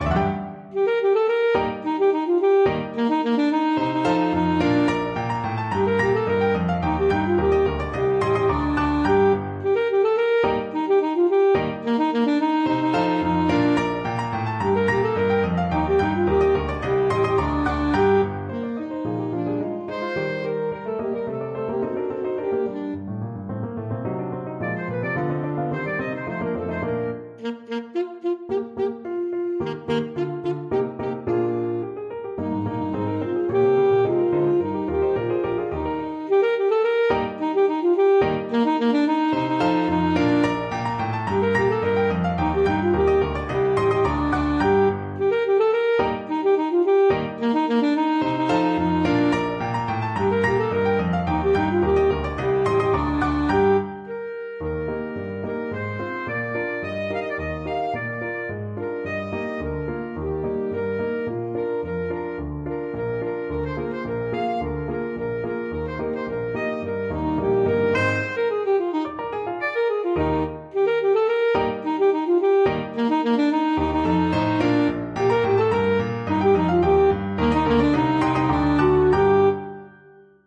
Alto Saxophone
2/2 (View more 2/2 Music)
Allegro ridicolo = c. 108 (View more music marked Allegro)
G minor (Sounding Pitch) E minor (Alto Saxophone in Eb) (View more G minor Music for Saxophone )
Classical (View more Classical Saxophone Music)